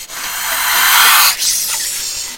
snake_hiss.wav